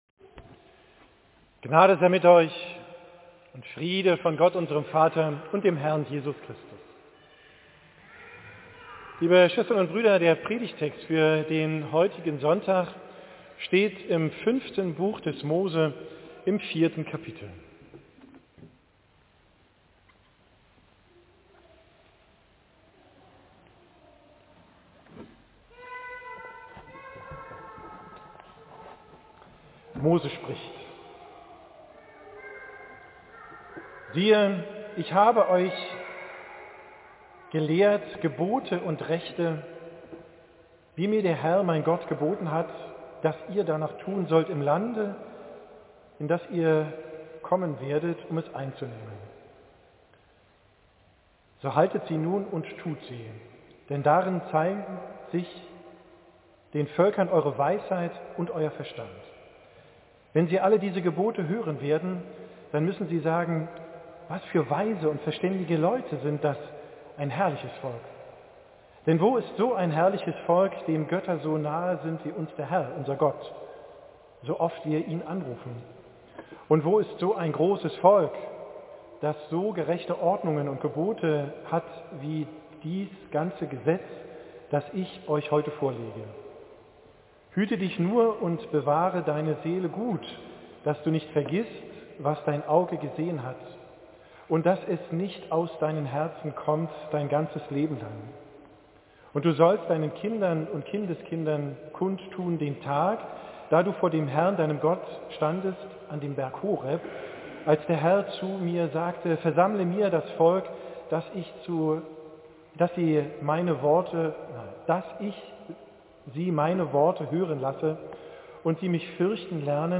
Predigt vom Israelsonntag, 13. VIII 2023